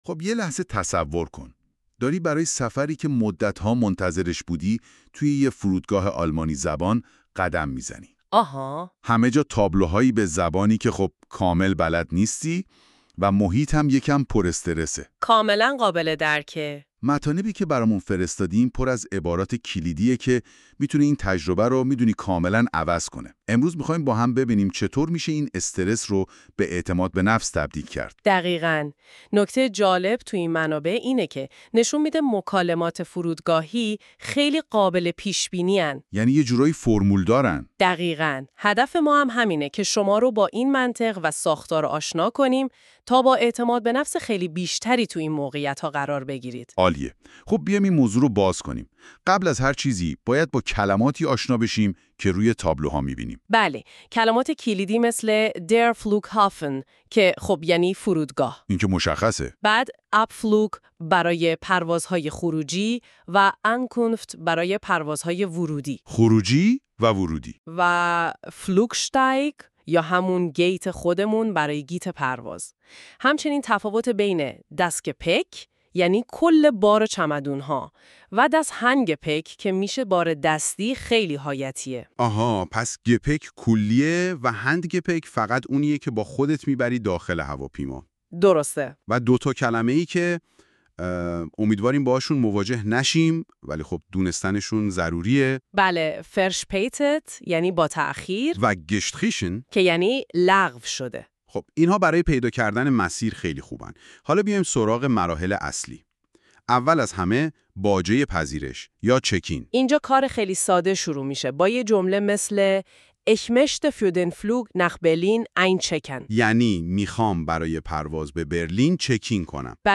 german-conversation-at-the-airport.mp3